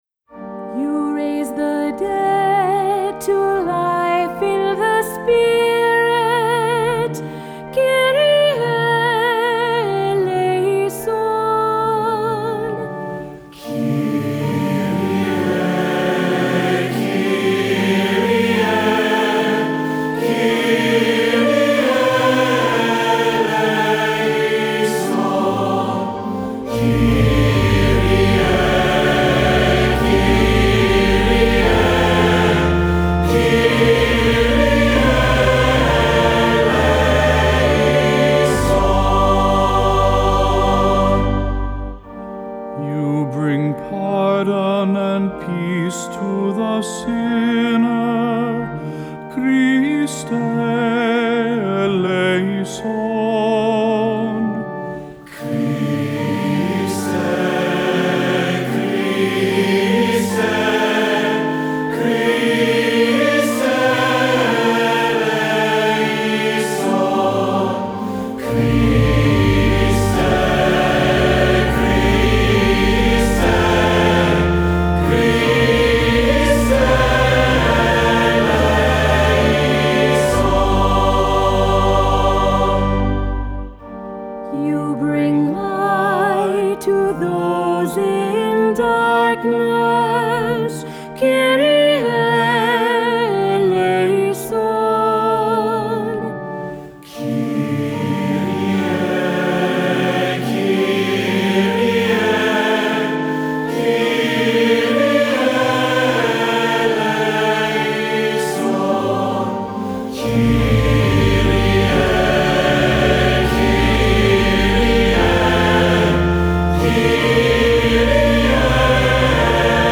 Voicing: SATB and Accompaniment